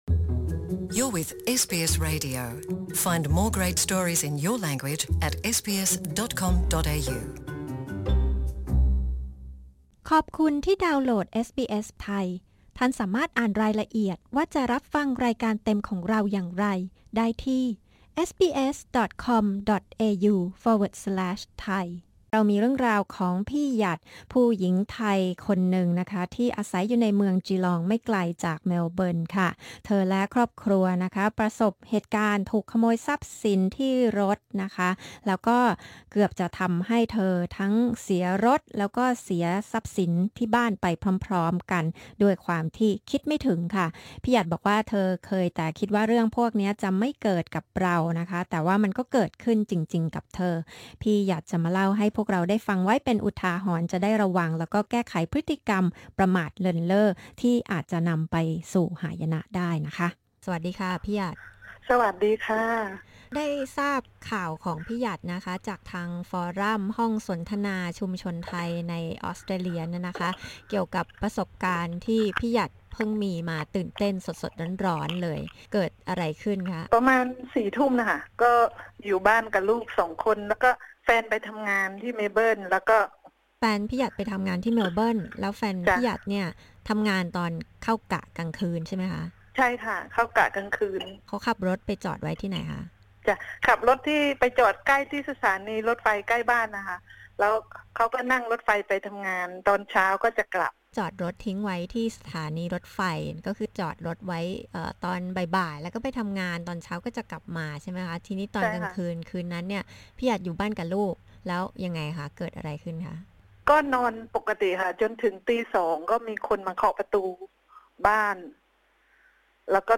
คนไทยในจีลอง เล่าประสบการณ์เฉียดฉิว ที่เกือบถูกโจรกรรมทรัพย์สินทั้งในรถยนตร์และที่บ้านในคืนเดียวกัน อะไรที่ทำให้หัวขโมยตามมาถึงบ้านได้ เธอเผยความประมาทที่เกือบนำไปสู่ความเสียหายจำนวนมาก